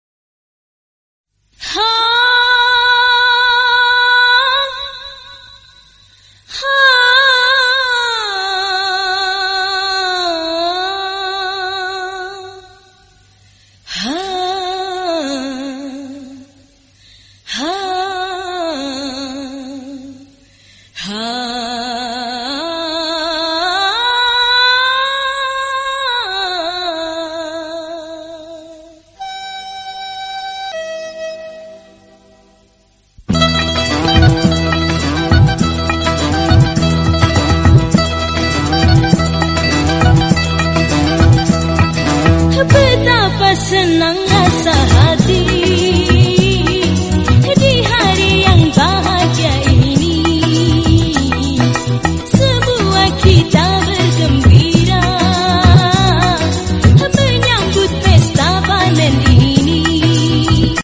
Suaranya Merdu Almarh.